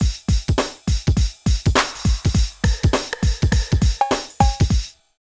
113 DRM LP-R.wav